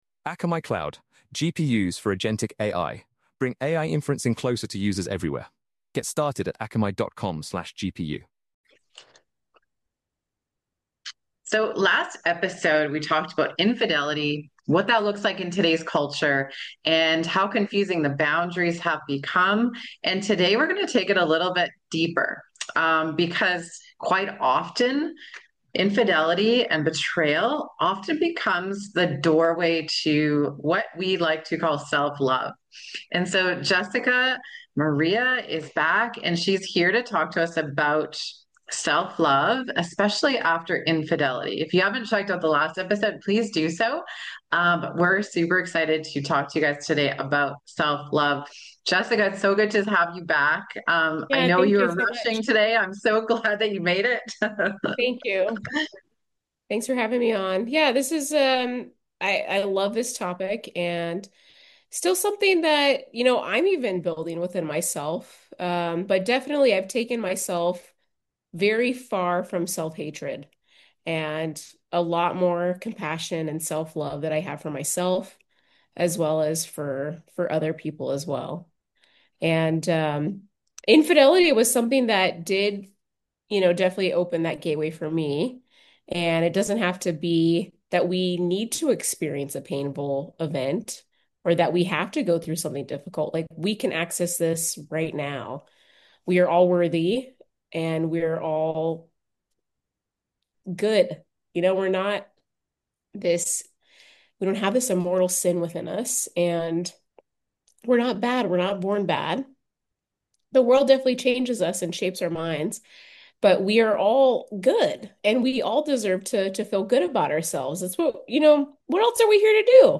This conversation focuses on practical tools that help you reconnect with yourself and begin healing from the inside out.